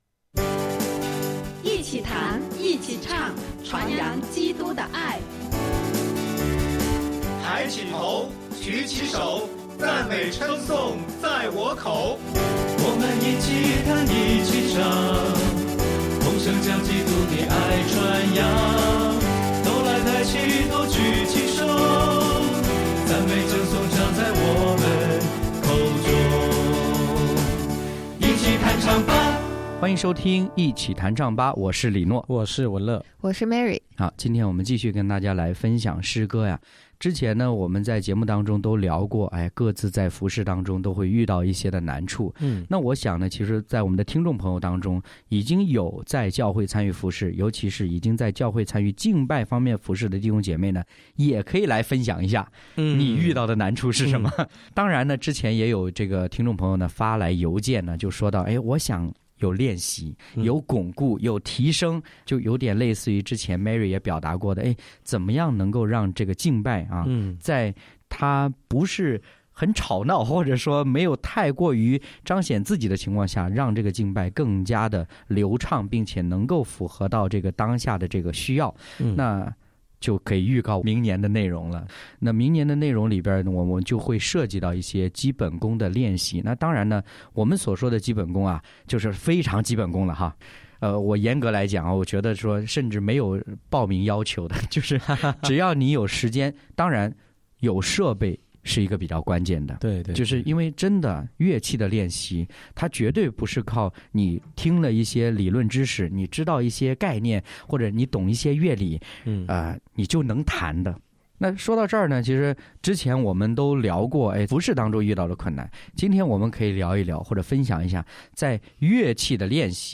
一起弹唱吧！
敬拜分享：操练不易，持守坚定；诗歌：《你的恩典》、《成为神迹的器皿》